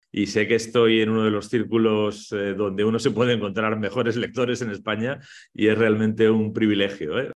El pasado 19 de abril, los célebres autores Javier Cercas y Lorenzo Silva compartieron con los lectores y lectoras ciegos de los más de 30 Clubes de Lectura (braille y sonoro) que la ONCE tiene en el país una tarde en la que, ambos escritores conversaron y compartieron sus experiencias literarias en el ya tradicional encuentro literario online con motivo del Día del Libro.